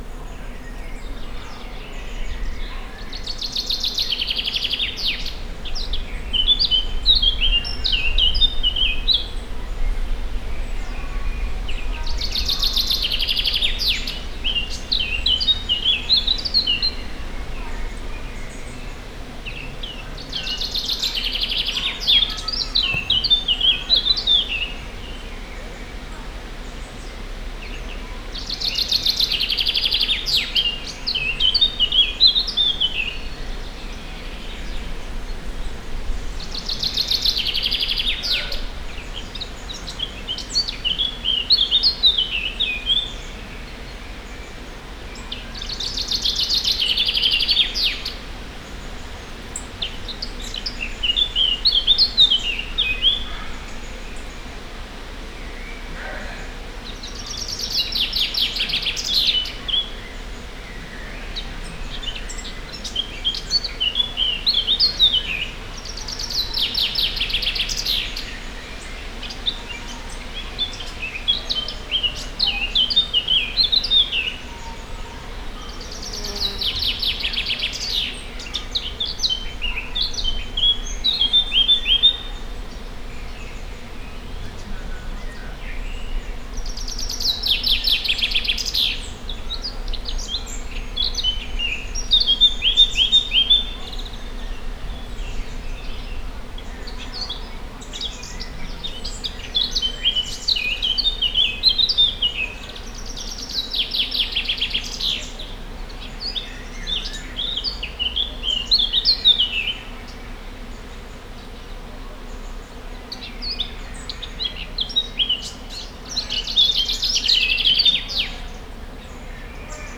balra_erdeipinty_jobbra_baratposzata_cardioid02.20.WAV